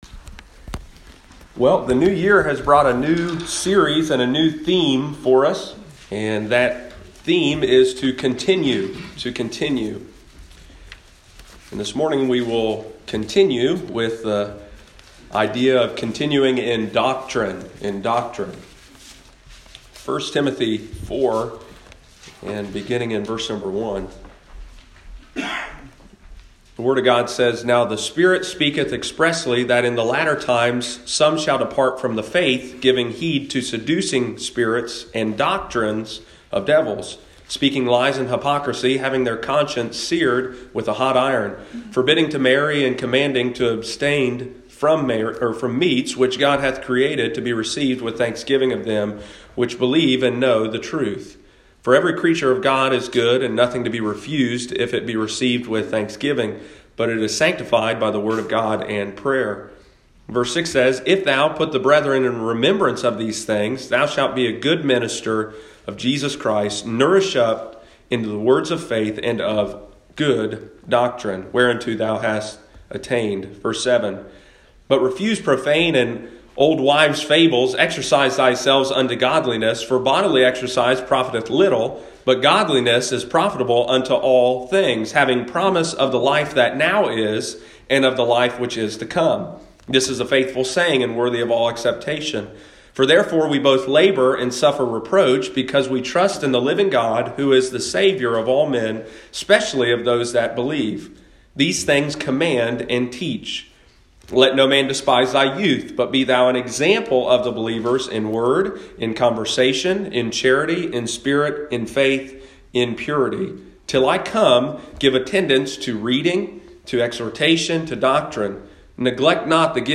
in the morning service